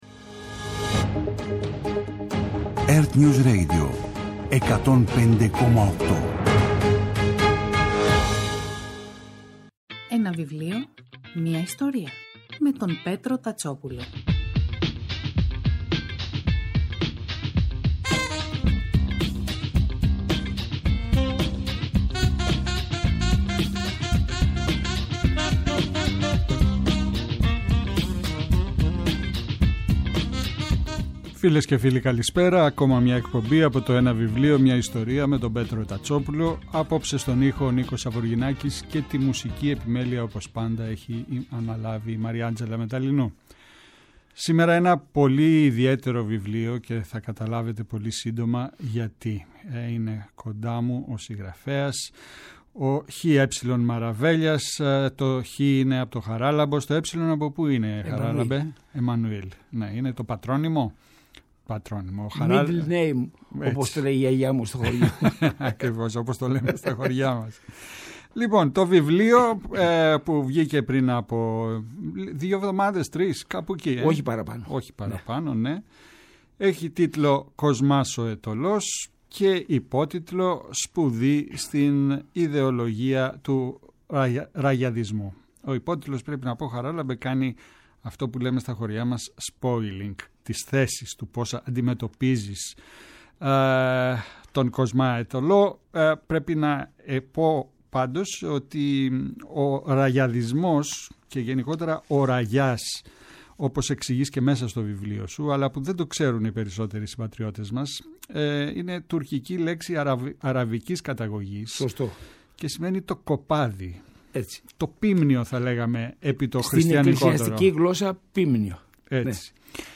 Κάθε Σάββατο και Κυριακή, στις 5 το απόγευμα στο ertnews radio της Ελληνικής Ραδιοφωνίας ο Πέτρος Τατσόπουλος, παρουσιάζει ένα συγγραφικό έργο, με έμφαση στην τρέχουσα εκδοτική παραγωγή, αλλά και παλαιότερες εκδόσεις.